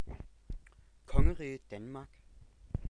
8. ^ Pronounced [ˈkʰɔŋəʁiːð̩ ˈtænmɑk]